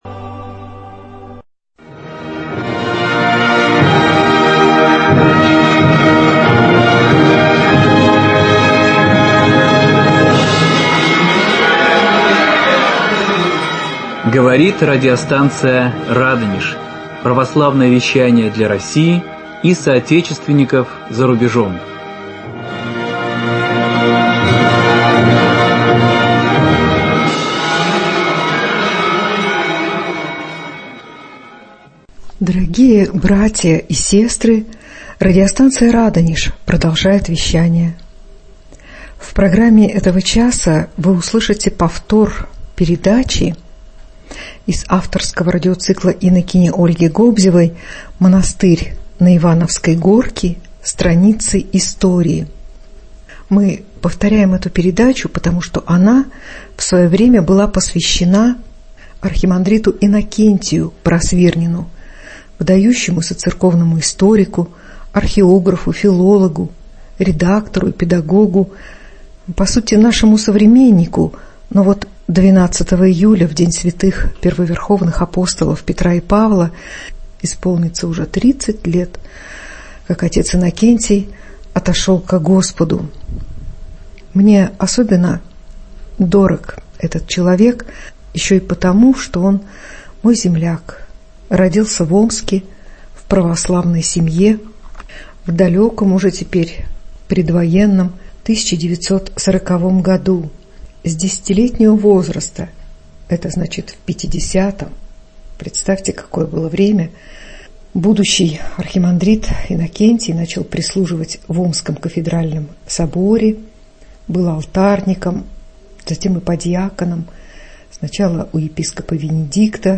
Автор и ведущая инокиня Ольга (Гобзева)